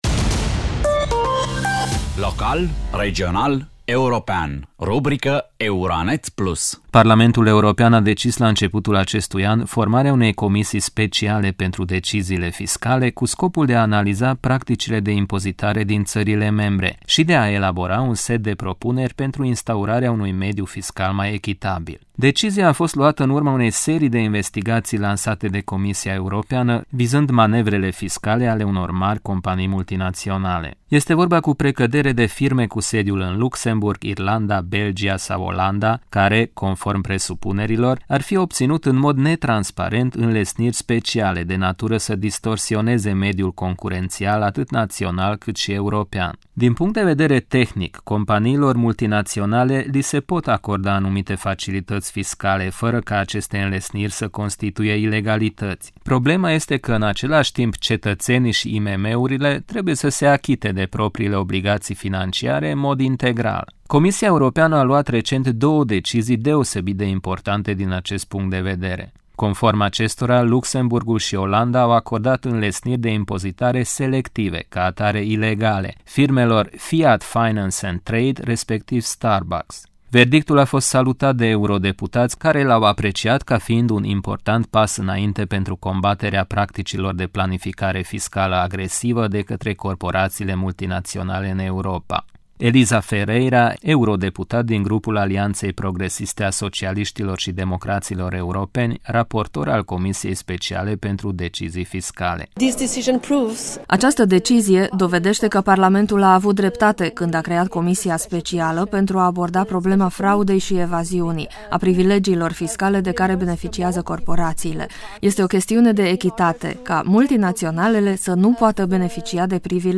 Elisa Ferreira, eurodeputat din Grupul Alianţei Progresiste a Socialiştilor şi Democraţilor Europeni, raportor al comisiei speciale pentru decizii fiscale: